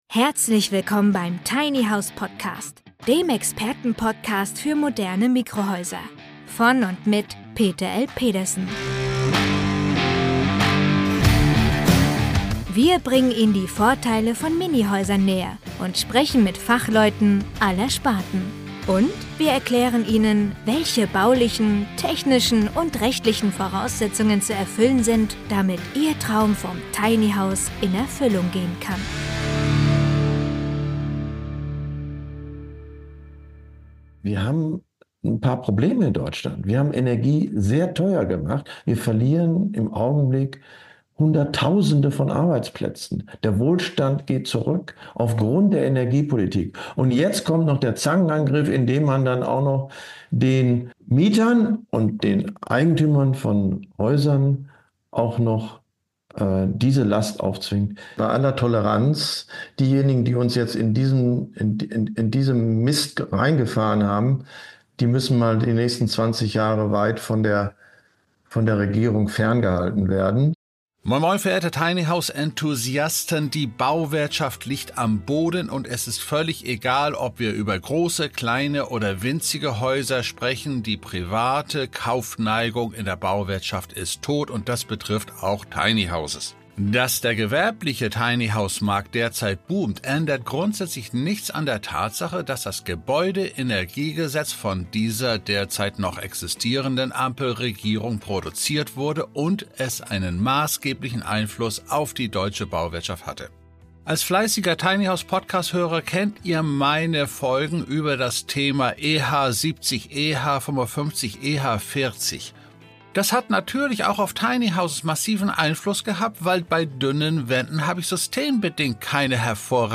Ex-Umweltsenator Prof. Dr. Fritz Vahrenholt im Interview